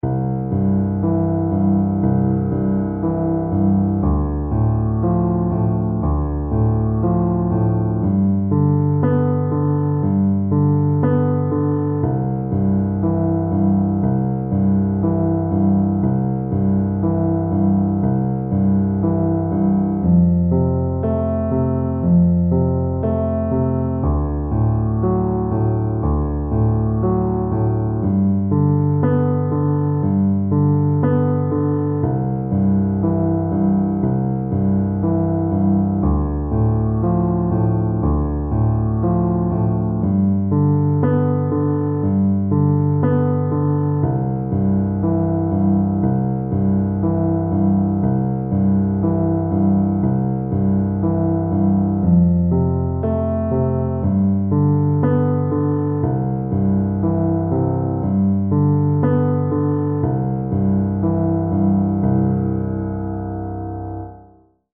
Paradiddle Kapitel 1 → Gebrochene Akkorde in der weiten Lage - Musikschule »allégro«